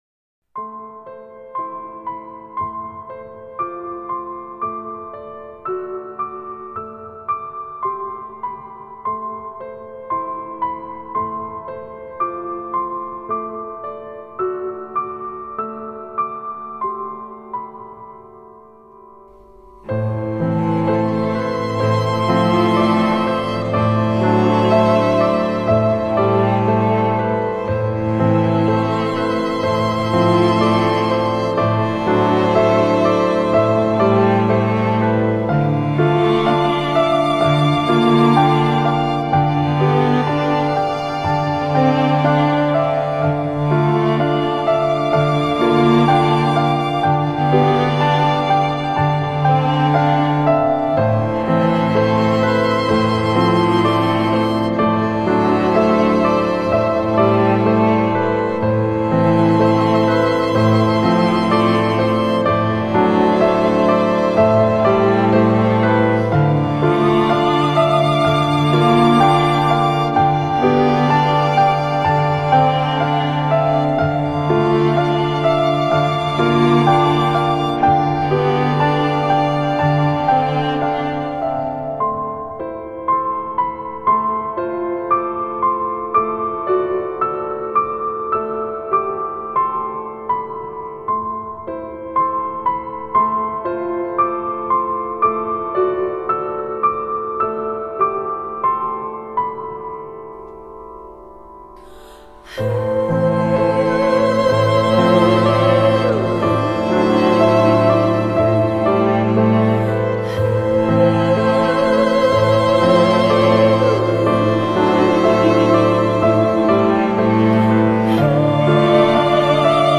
优美至极
钢琴演奏